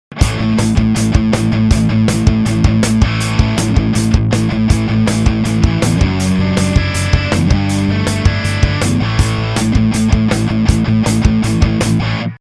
前回はドラムにかけてみましたが、今回は歪んだギターにかけてみましょう。
まず、録音してみた、ギターのデータはこれです。
KReserchのプラグインはモノラルトラックには立ち上がらないので、モノラルのソースでもステレオトラックに録音します。